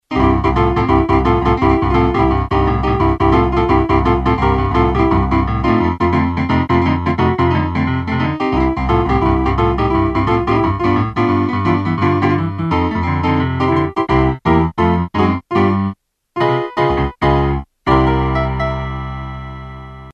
Here's that piano again.